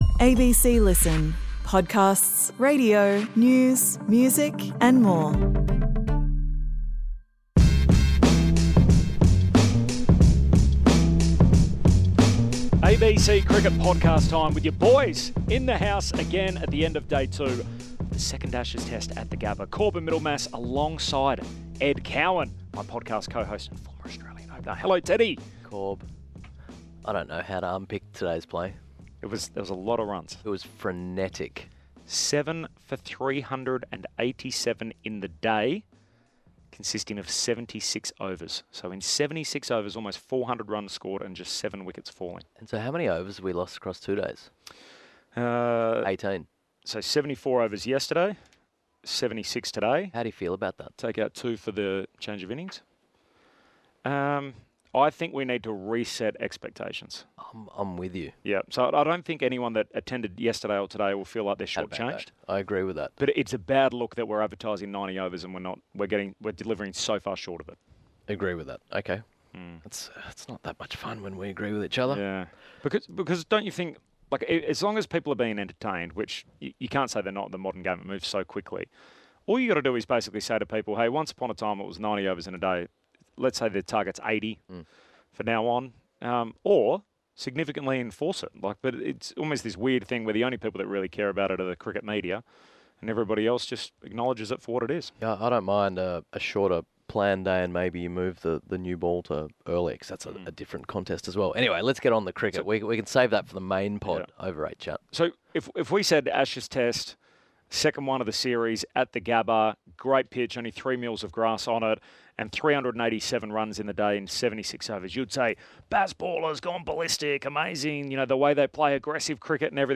The pair discuss the key players and big issues that are dominating the cricket agenda.